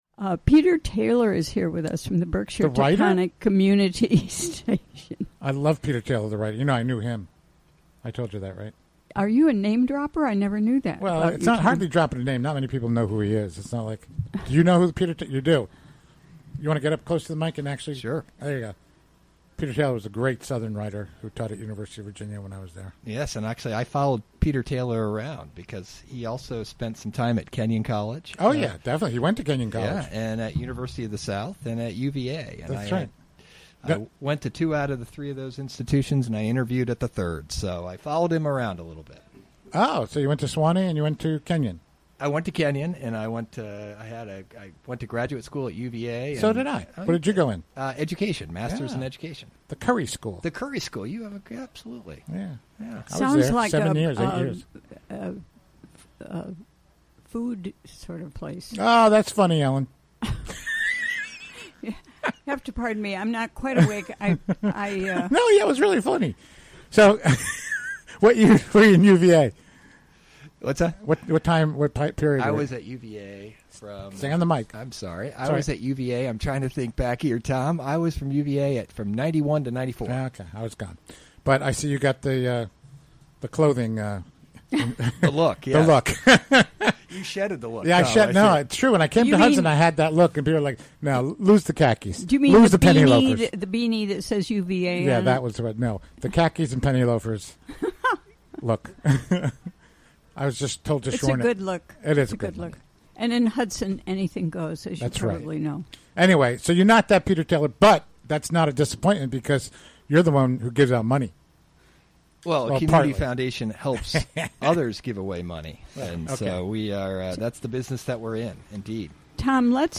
Recorded during the WGXC Afternoon Show Thursday, June 22, 2017.